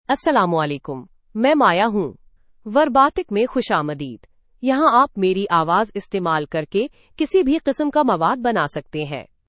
Maya is a female AI voice for Urdu (India).
Voice sample
Listen to Maya's female Urdu voice.
Female
Maya delivers clear pronunciation with authentic India Urdu intonation, making your content sound professionally produced.